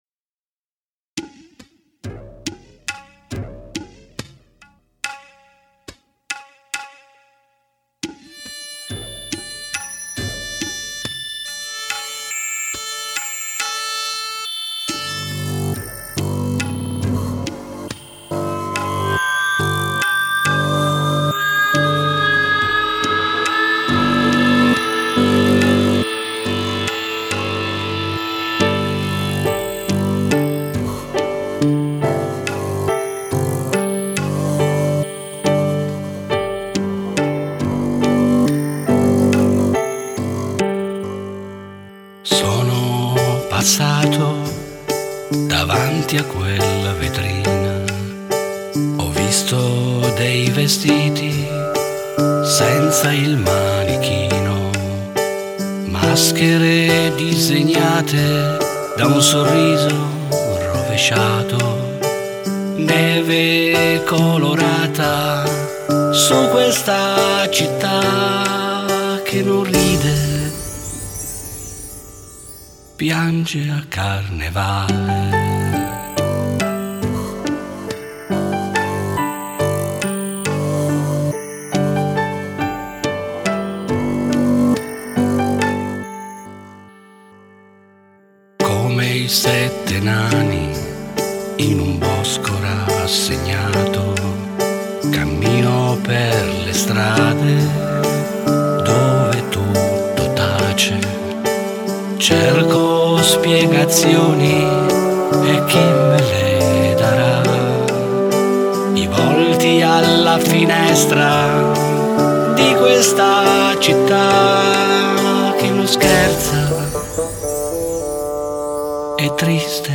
al pianoforte e alle tastiere